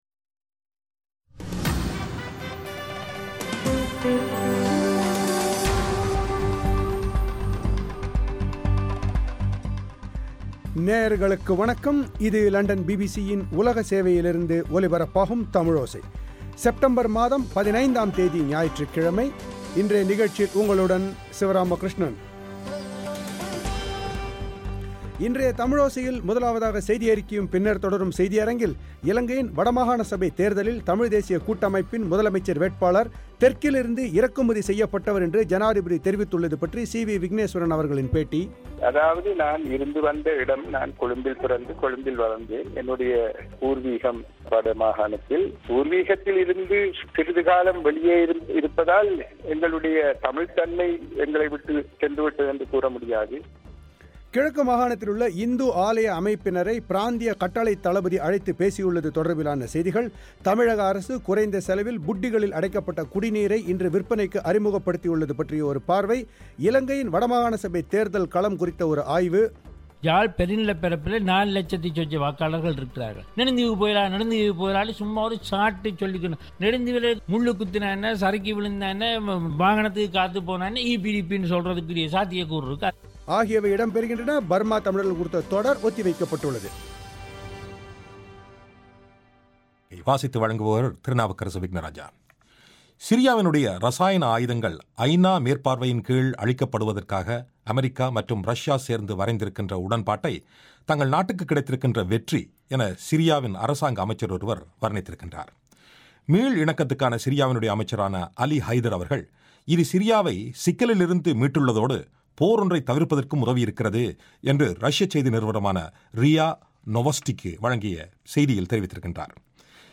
இலங்கையின் வட மாகாண சபைத் தேர்தலில், தமிழ்த் தேசியக் கூட்டமைப்பின் முதலமைச்சர் வேட்பாளர் தெற்கிலிருந்து இறக்குமதி செய்யப்பட்டவர் என்று ஜனாதிபதி தெரிவித்துள்ளது பற்றி சி.வி விக்னேஸ்வரனின் பேட்டி,